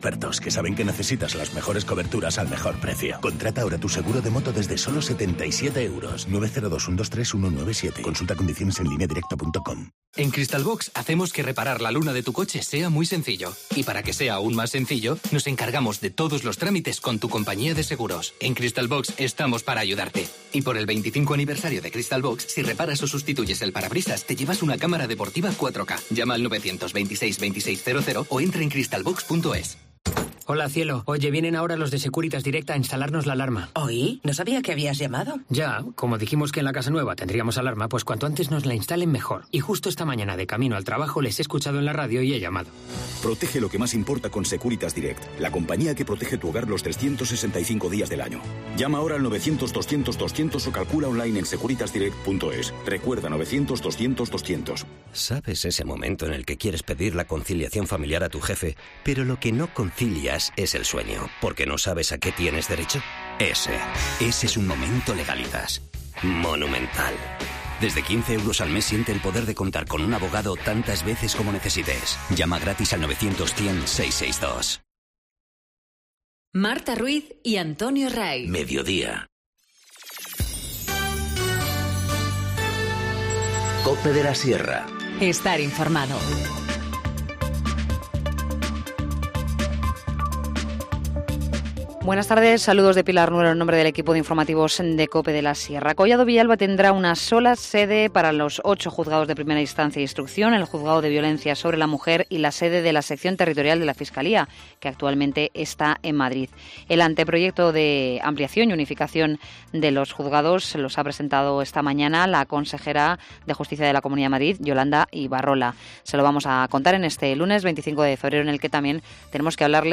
Informativo Mediodía 25 febrero- 1420h